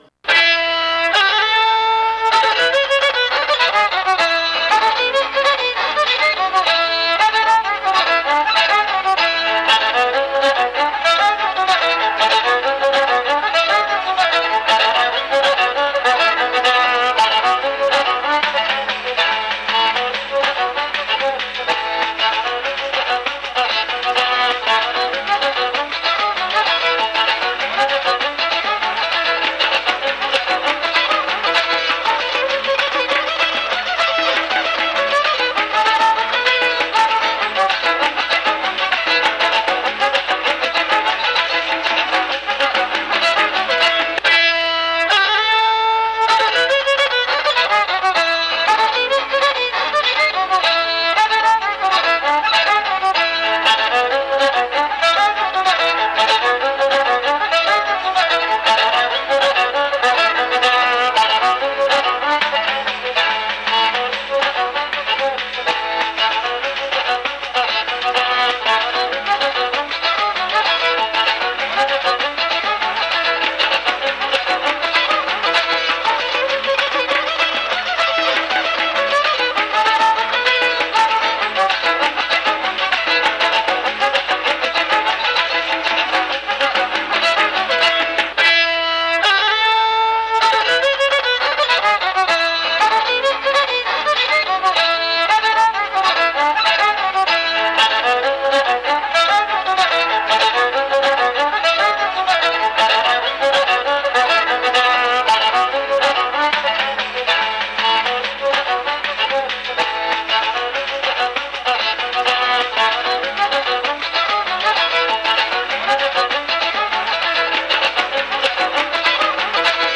Compõe o grupo o mestre e o tocador de viola, o contramestre e o tocador de meia cuia, dois guias e os participantes dançadores.
instrumental